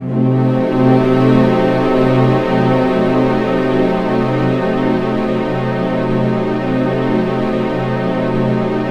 TENSION01.-R.wav